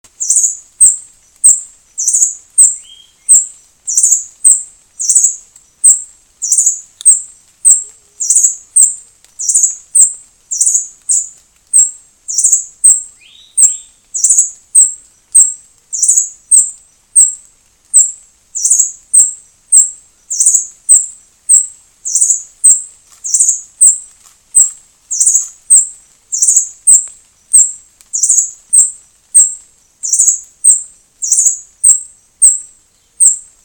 Scientific name: Phaethornis malaris margarettae
English Name: Great-billed Hermit
Detailed location: Estação Ecológica de Murici
Condition: Wild
Certainty: Photographed, Recorded vocal